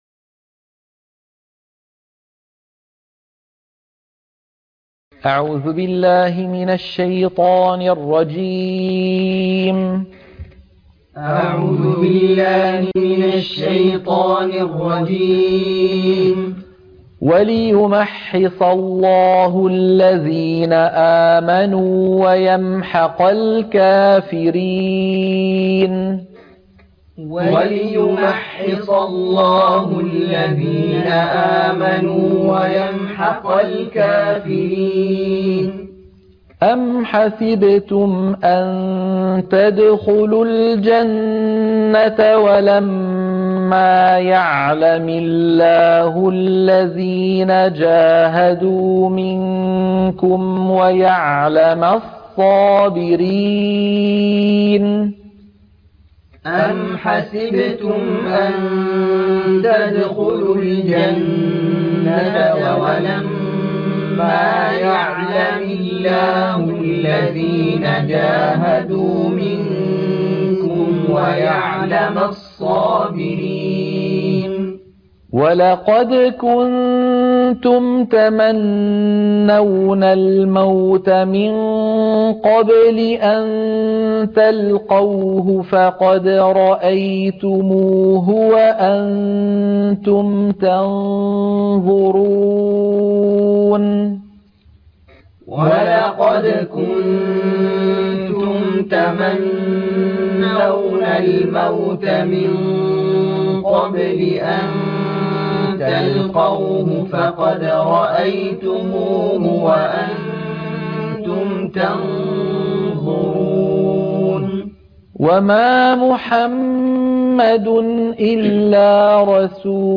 تلقين سورة آل عمران - الصفحة 68 التلاوة المنهجية - الشيخ أيمن سويد